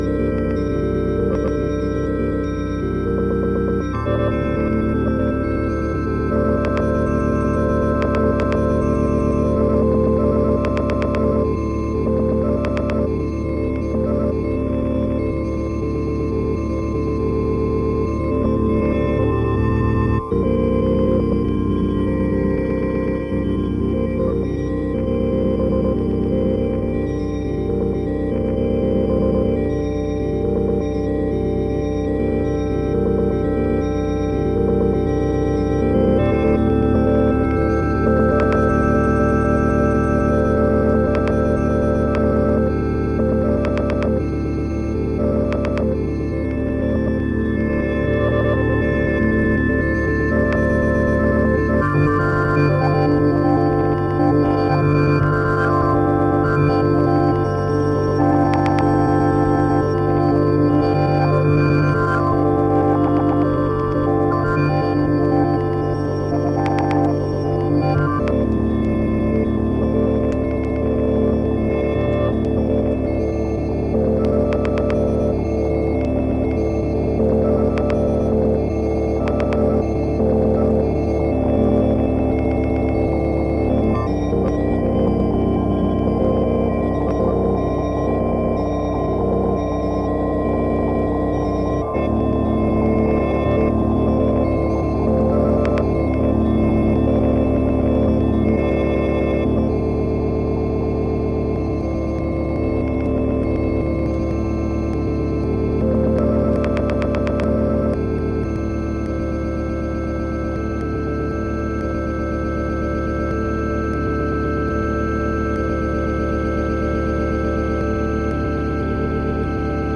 percussionista e vibrafonista
a sonoridade quente e confortável do erro controlado
é o som ambiente captado durante as gravações.